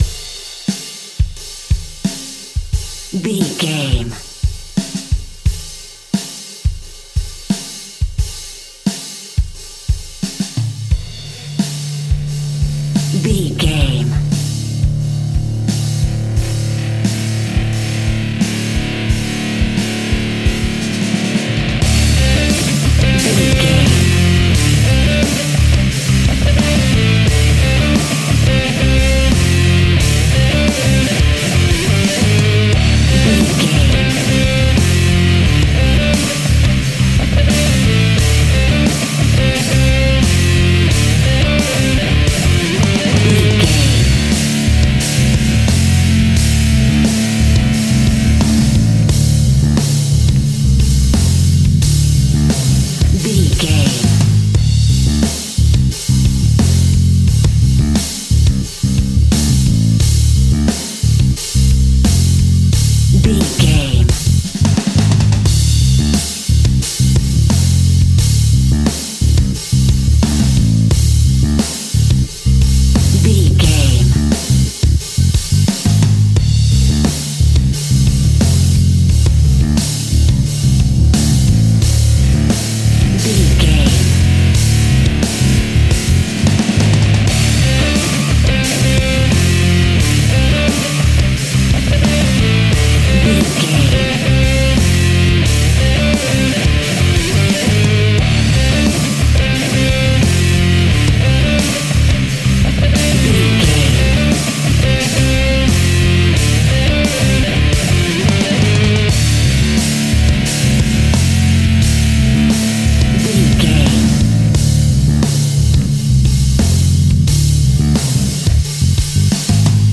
Epic / Action
Fast paced
Aeolian/Minor
hard rock
heavy metal
blues rock
distortion
punk metal
rock instrumentals
Rock Bass
Rock Drums
heavy drums
distorted guitars
hammond organ